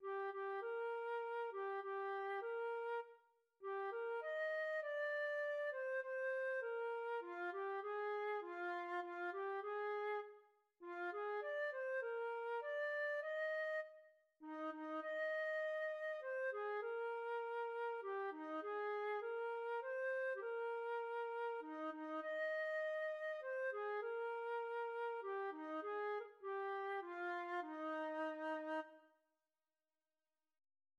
Жанр колыбельная песня
Мелодия